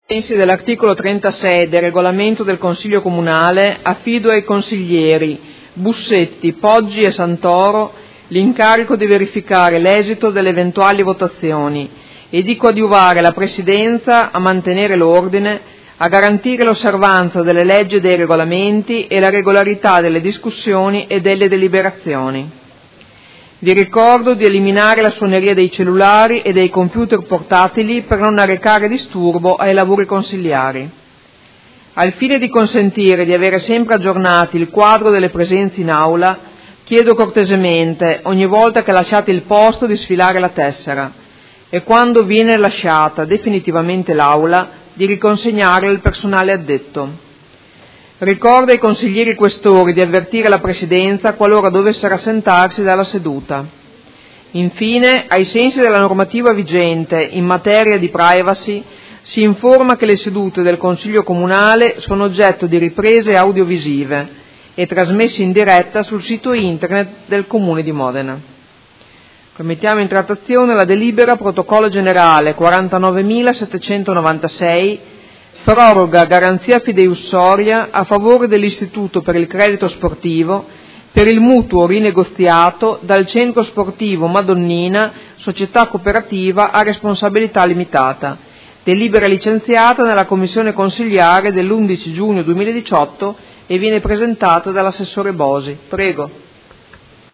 Seduta del 21/01/2018 Apre ai lavori del Consiglio Comunale
Presidentessa